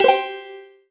get_trophies_01.wav